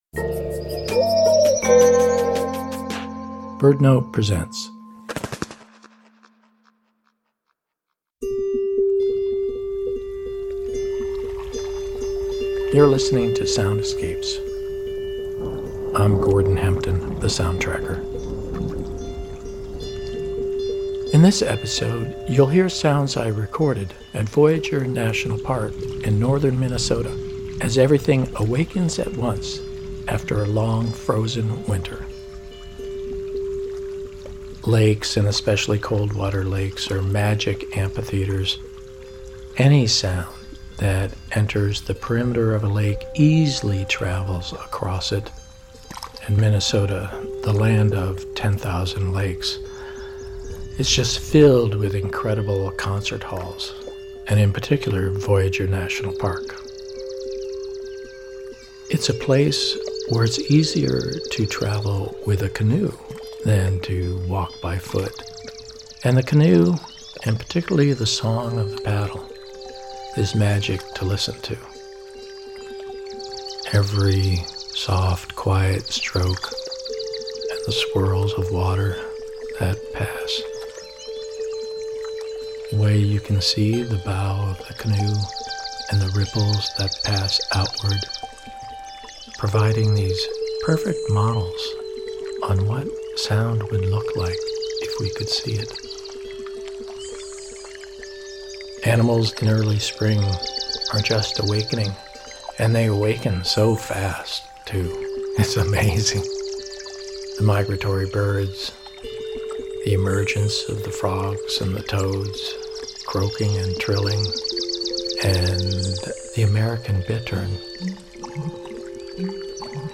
After a long winter in northern Minnesota, everything seems to awaken at once. From the songs of migratory birds to the croaks of frogs and toads, we can witness a wonderful rejuvenation.